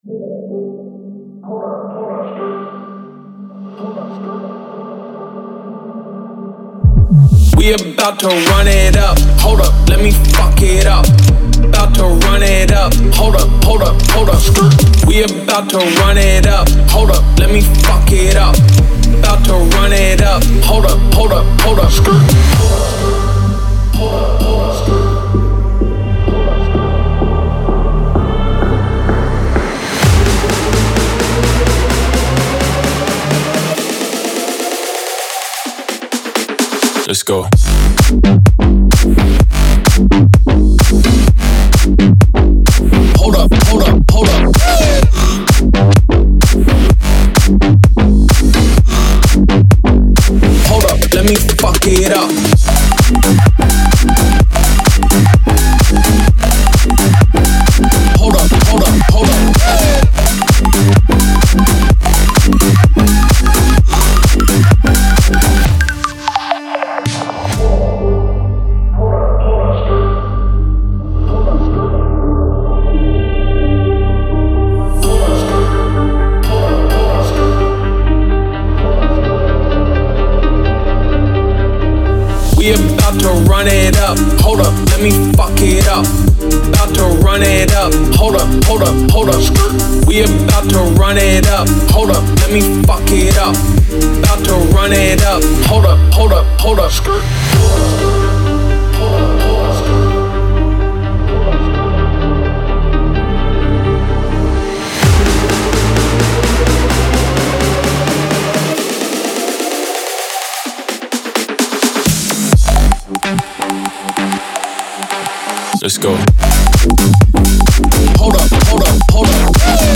пронизан эмоциями, что делает исполнение живым и искренним